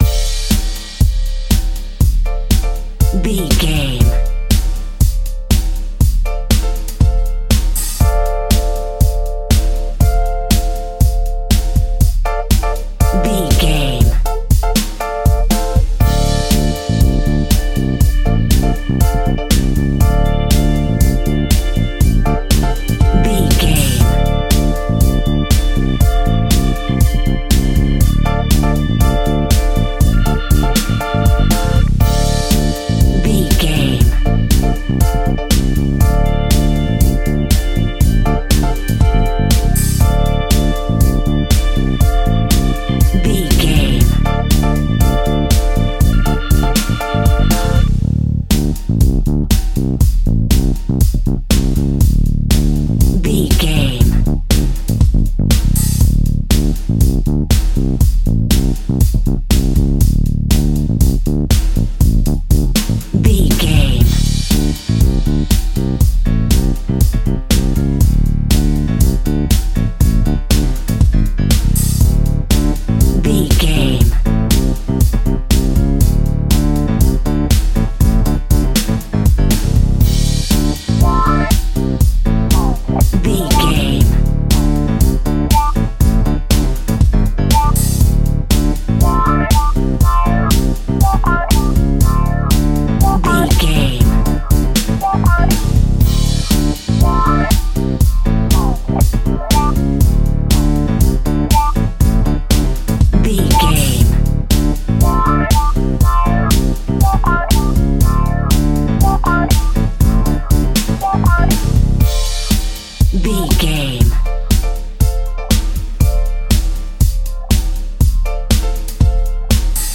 Aeolian/Minor
funky
groovy
uplifting
driving
energetic
strings
brass
bass guitar
electric guitar
electric organ
synthesiser
drums
funky house
disco house
electro funk
upbeat
synth leads
Synth Pads
synth bass
drum machines